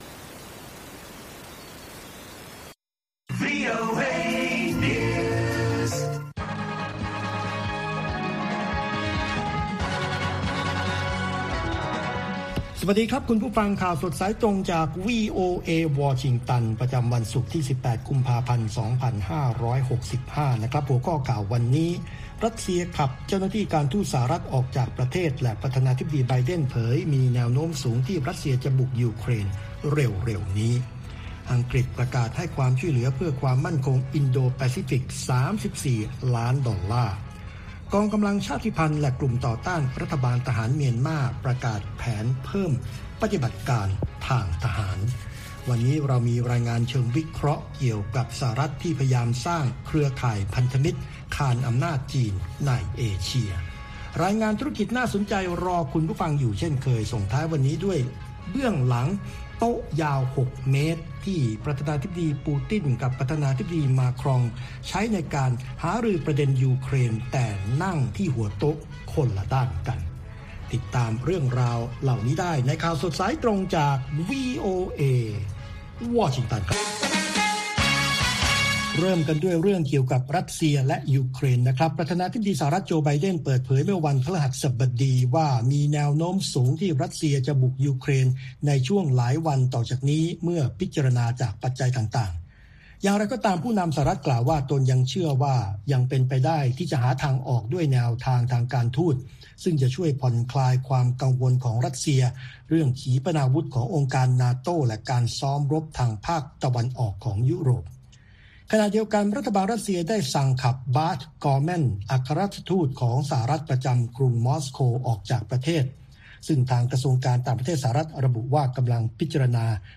ข่าวสดสายตรงจากวีโอเอ ภาคภาษาไทย ประจำวันศุกร์ที่ 18 กุมภาพันธ์ ตามเวลาในประเทศไทย